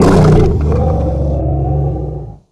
chimera_death_1.ogg